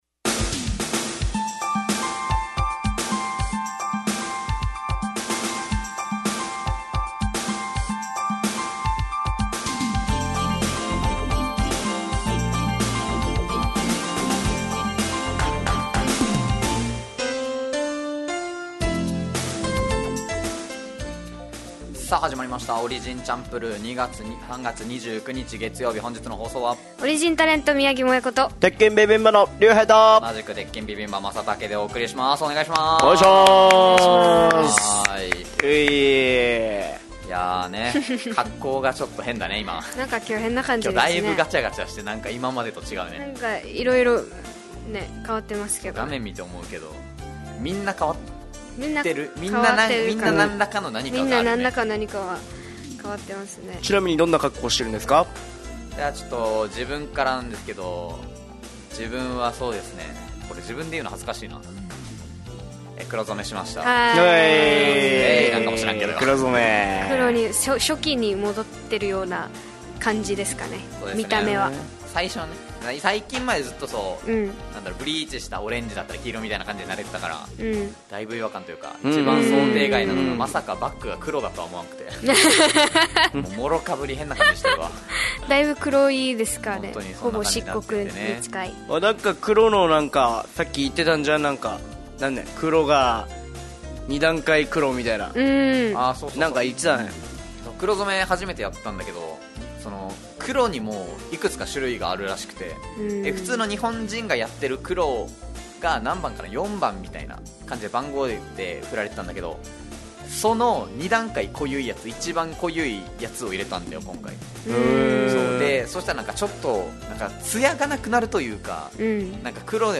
fm那覇がお届けする沖縄のお笑い集団オリジンと劇団O.Z.Eメンバー出演のバラエティ番組